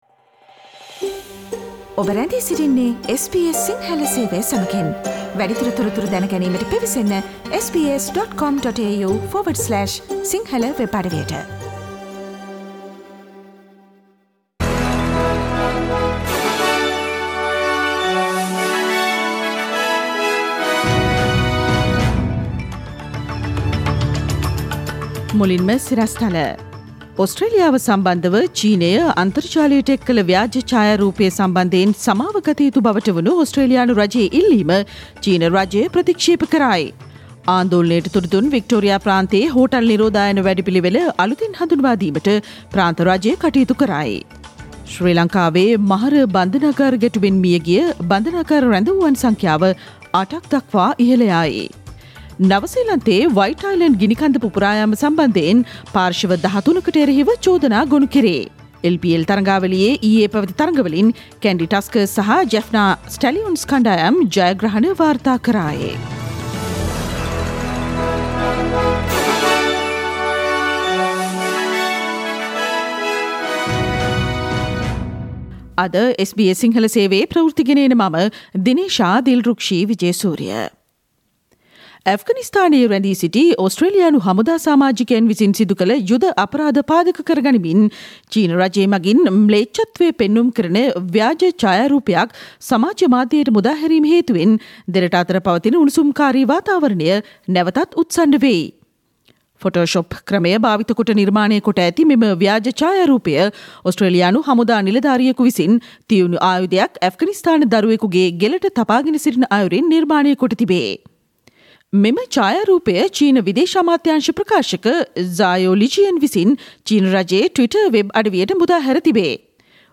Today’s news bulletin of SBS Sinhala radio – Tuesday 1 December 2020.